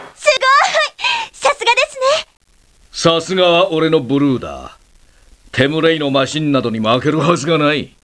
しかし、クリアできないときは得点によってモーリンのコメントが変わる。また、クリアしたときは被弾率によってコメントが変わる。